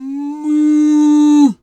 cow_2_moo_05.wav